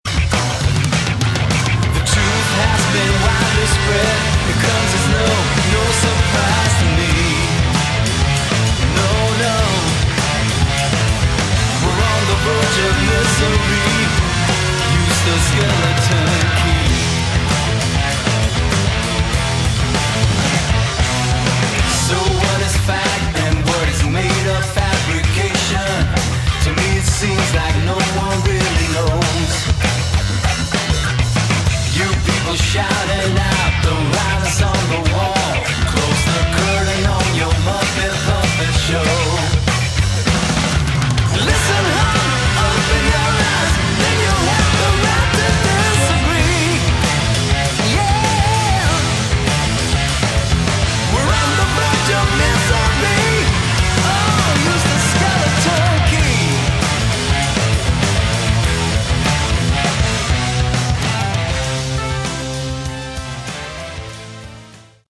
Category: Melodic Hard Rock
lead & backing vocals
lead & rhythm guitar, backing vocals
drums, percussion, backing vocals
organ, keyboards, backing vocals